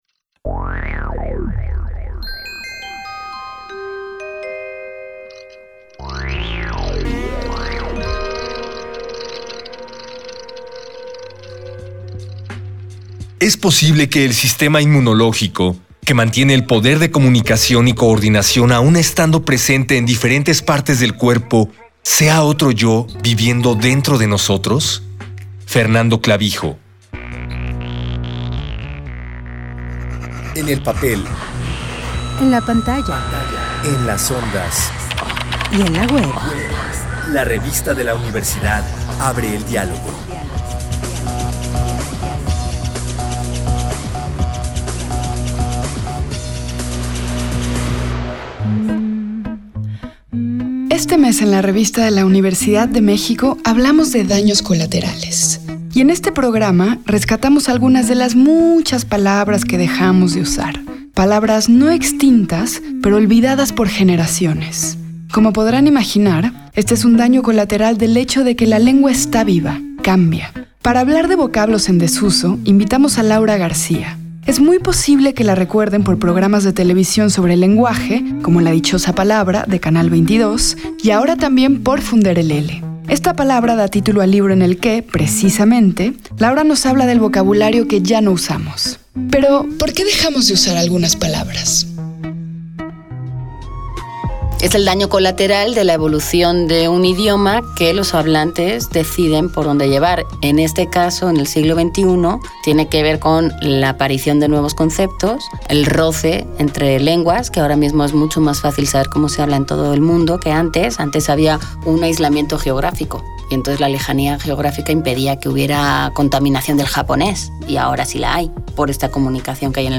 Este programa es una coproducción de la Revista de la Universidad de México y Radio UNAM. Fue transmitido el jueves 13 de septiembre de 2018 por el 96.1 FM.